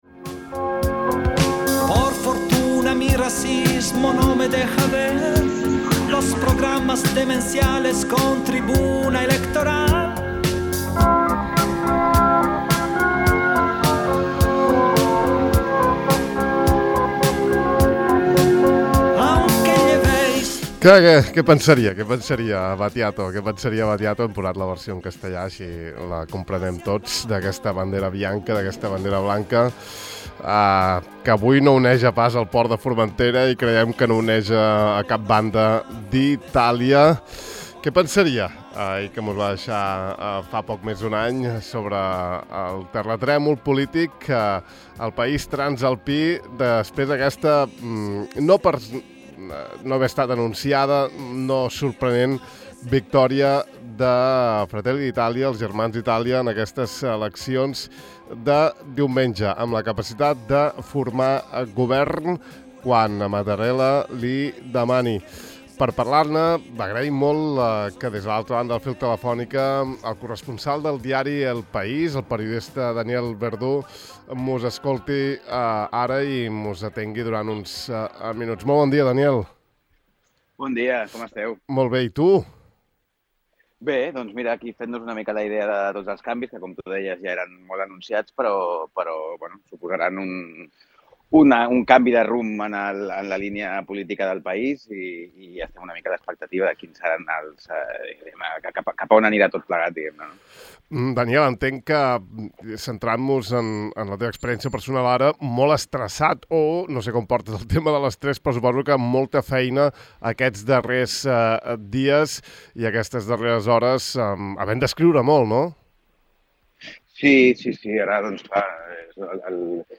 Avui hem tingut el privilegi de conversar una estona